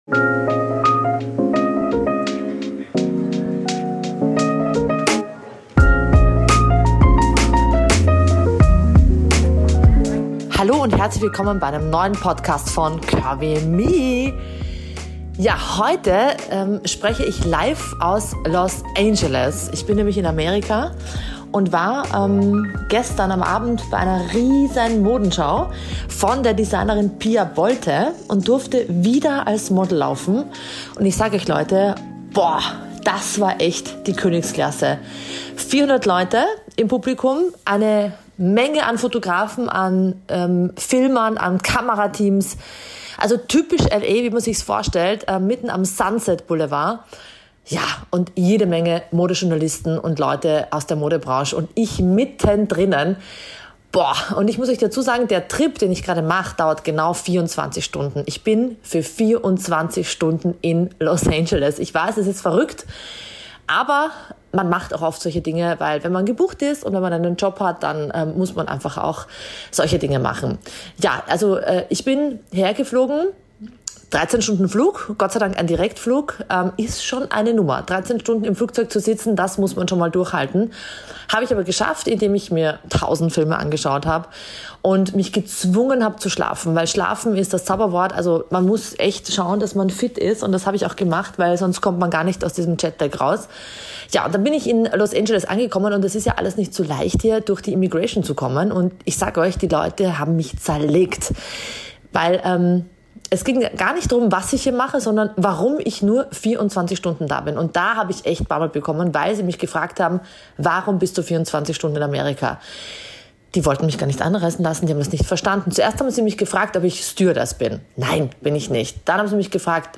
#55 - Live aus Los Angeles - Mein erster Catwalk auf der Fashion Week